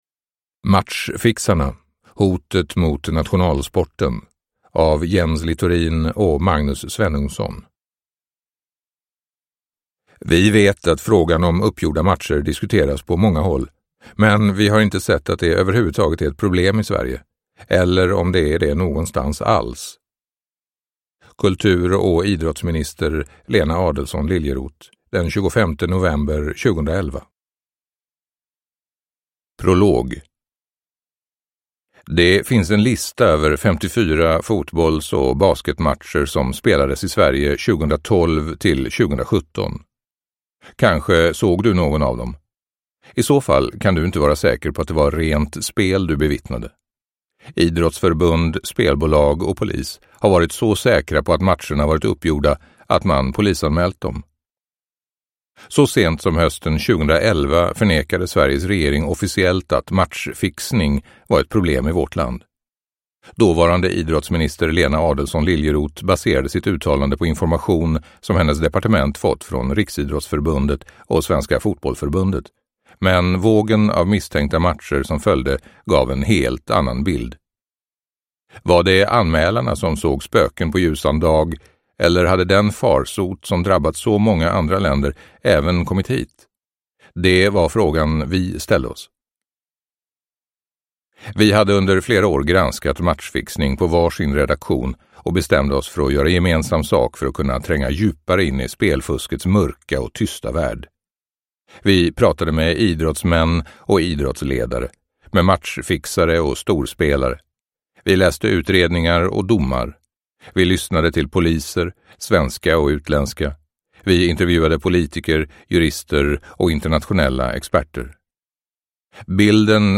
Matchfixarna: hotet mot nationalsporten – Ljudbok – Laddas ner